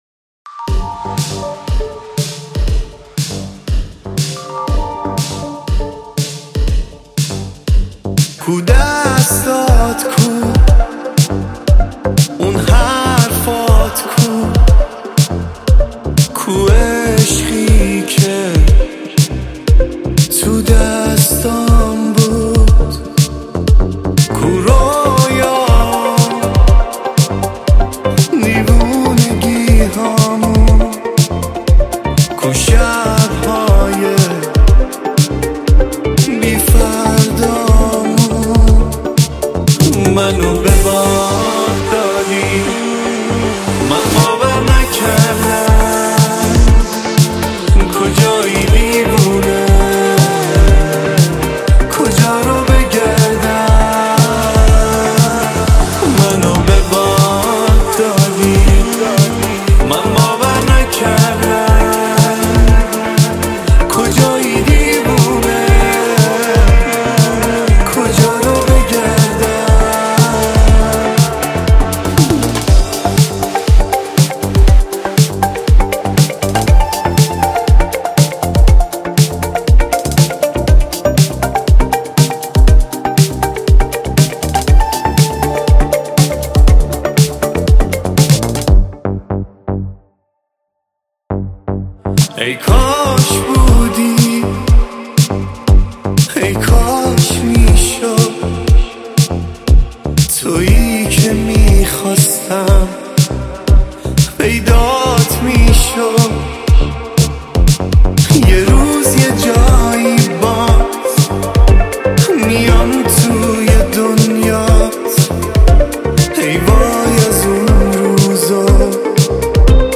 دانلود آهنگ شاد با کیفیت ۱۲۸ MP3 ۳ MB